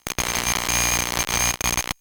Звуки радио, радиопомех
Погрузитесь в атмосферу ретро-радио с коллекцией звуков помех, шипения и настройки частот.
Шумы в AM радиовещании